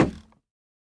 Minecraft Version Minecraft Version snapshot Latest Release | Latest Snapshot snapshot / assets / minecraft / sounds / block / bamboo_wood / step1.ogg Compare With Compare With Latest Release | Latest Snapshot
step1.ogg